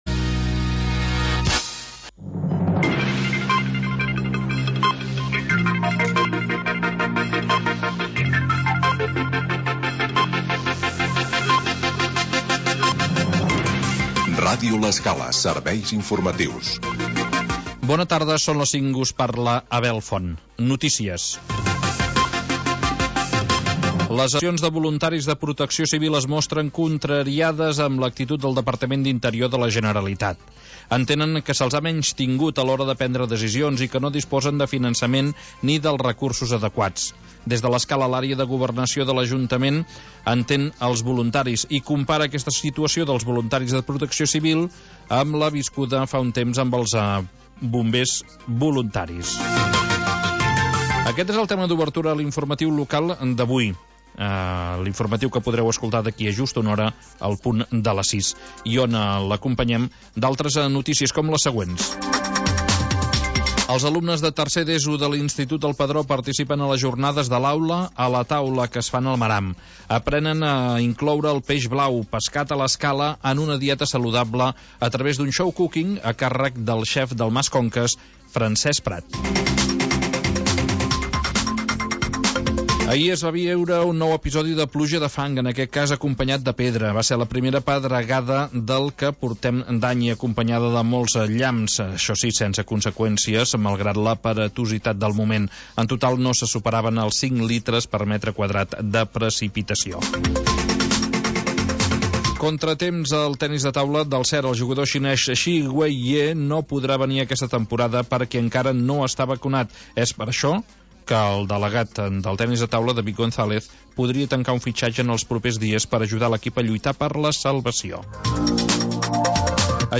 Magazín territorial que parla del mar